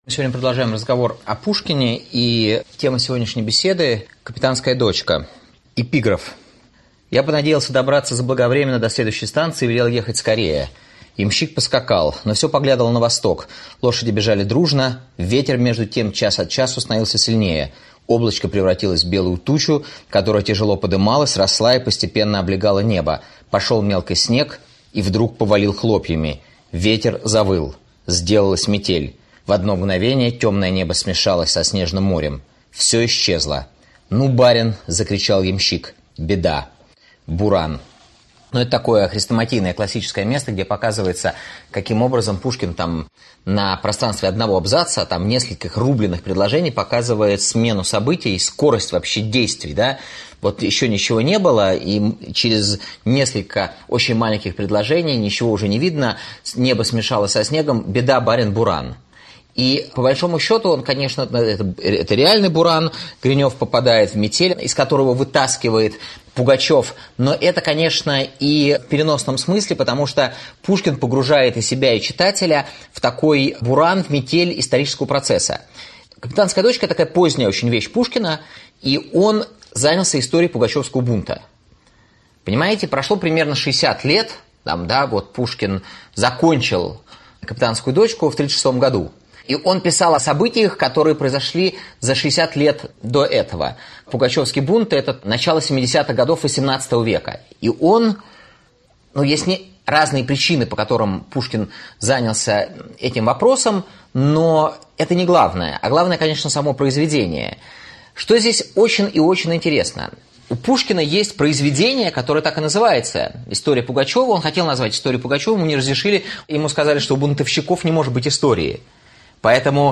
Аудиокнига Пушкин. Капитанская дочка. Нелишние люди русской литературы | Библиотека аудиокниг